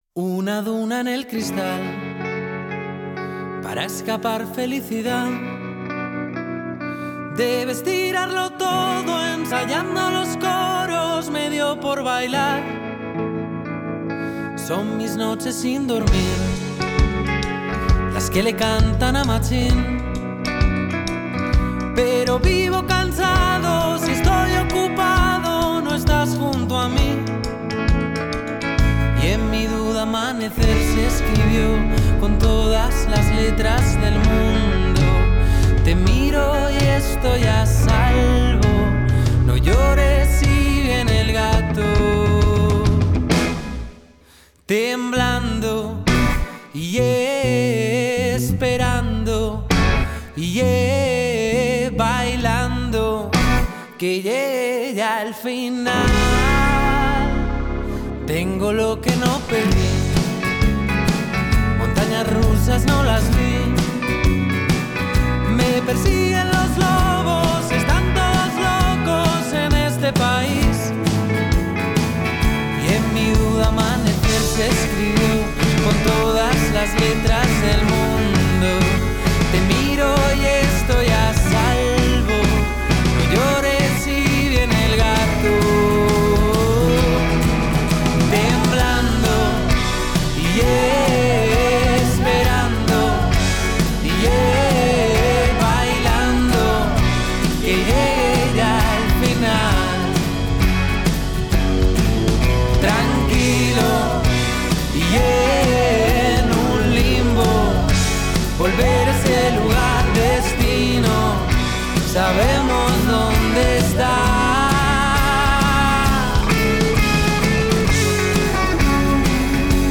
Es un disco más melancólico y más trabajado”, ha explicado.